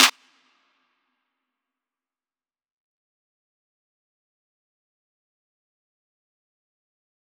DMV3_Snare 13.wav